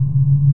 Low_Rumble01.wav